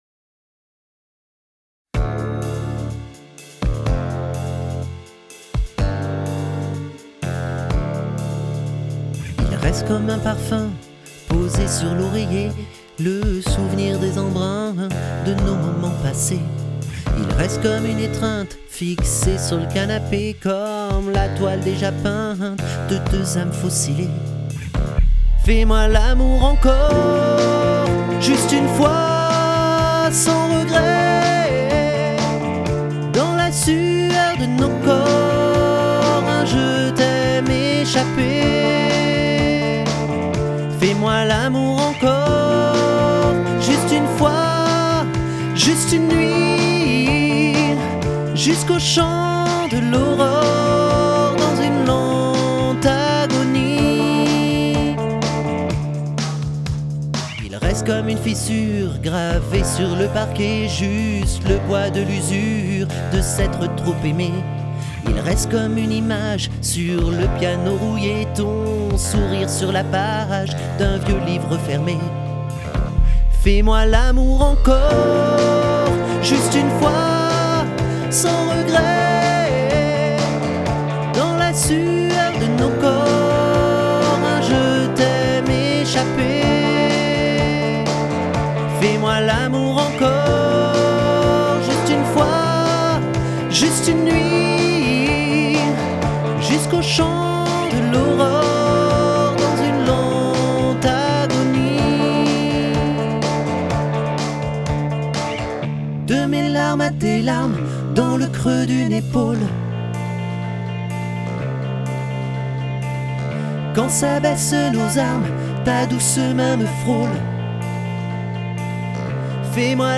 Tessiture: Barython Martin / Ténor